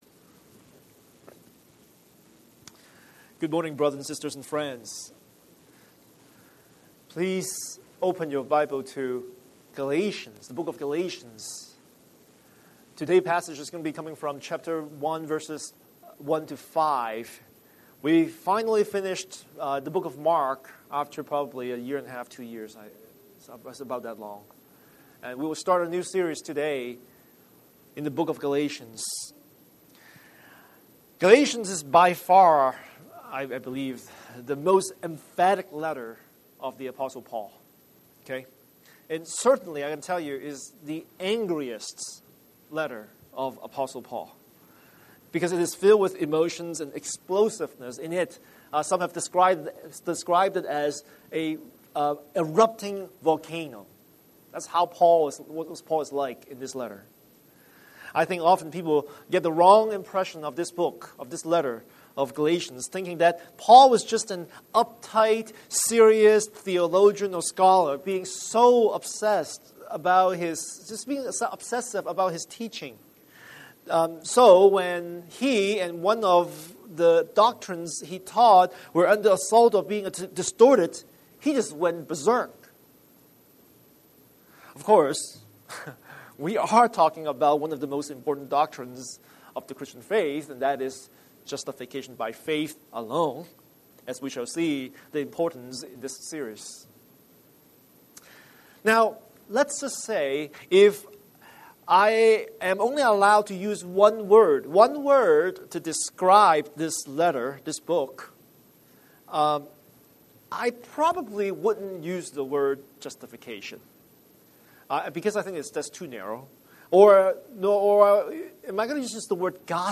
Scripture: Galatians 1:1-5 Series: Sunday Sermon